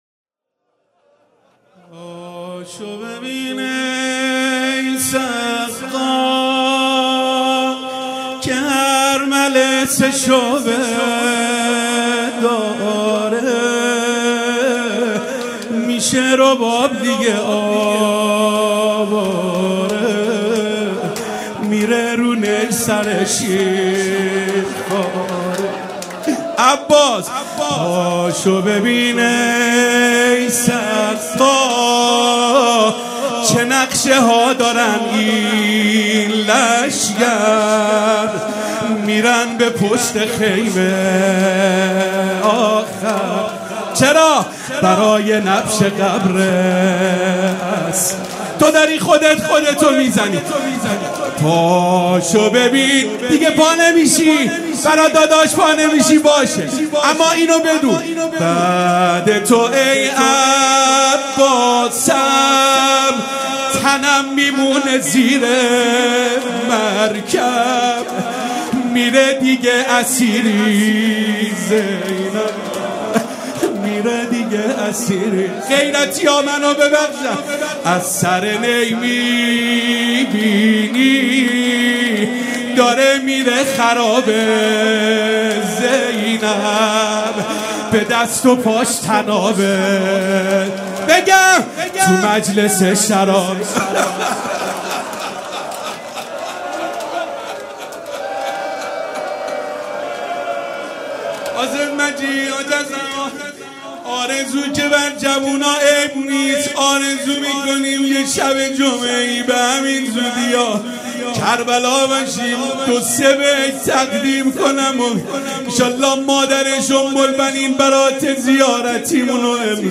روضه
مراسم مناجات شب بیست و چهارم ماه رمضان
حسینیه ریحانة‌الحسین(س)